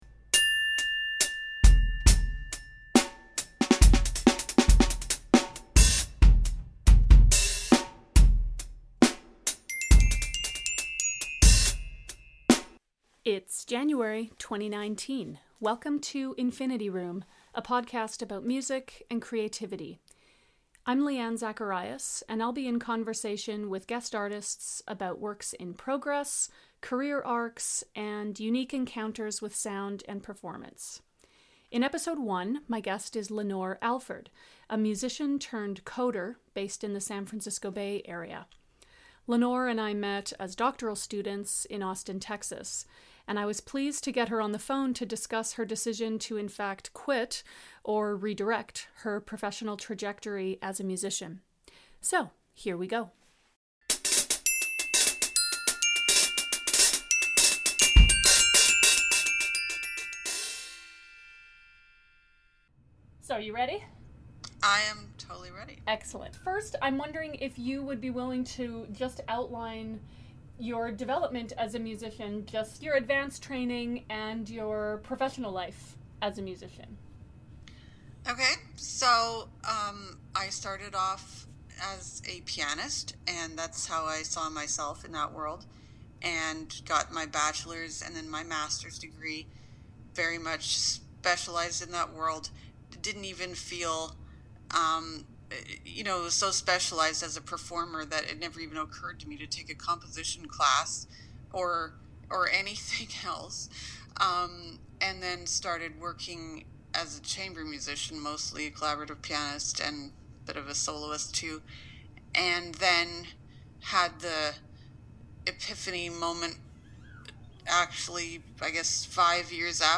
Introducing Infinity Room, a podcast series exploring music and creativity through interviews, conversations and intersecting leitmotifs.